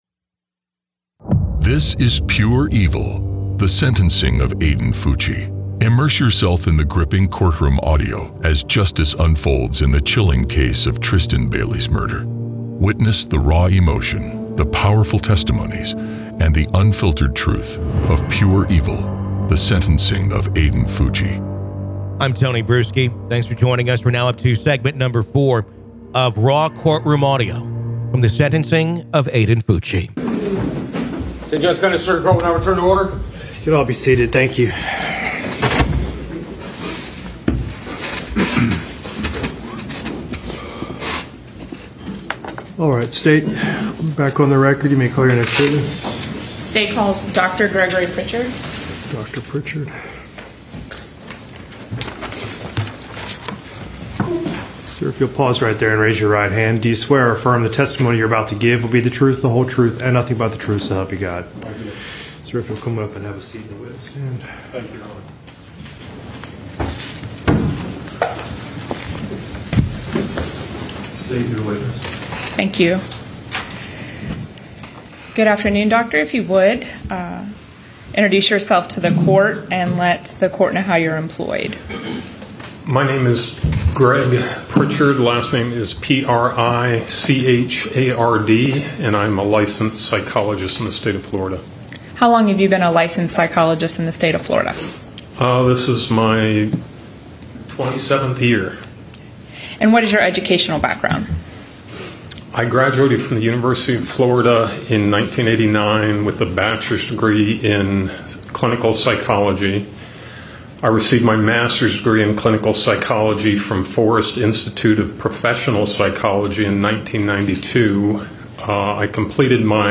This gripping podcast series transports you to the heart of the legal proceedings, providing exclusive access to the in-court audio as the prosecution and defense lay out their arguments, witnesses testify, and emotions run high.